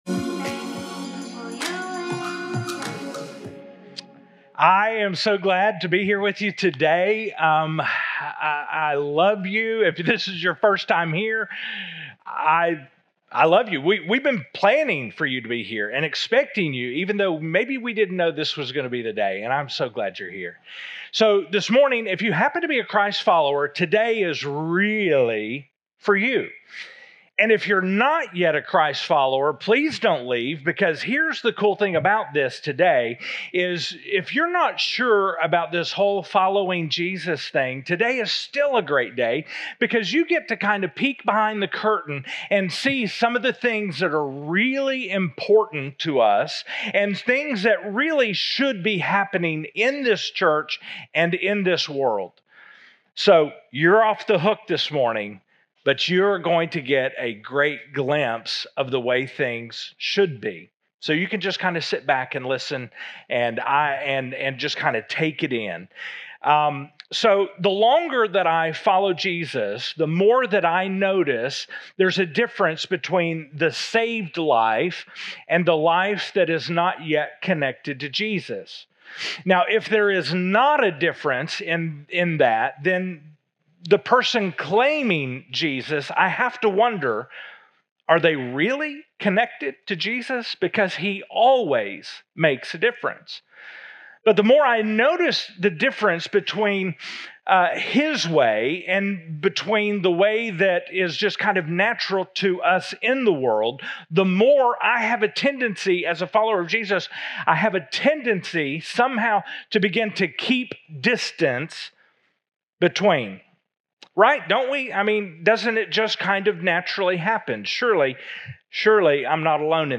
2025 Current Sermon 7 OF 8|JESUS 2.O We throw lifelines, not rocks.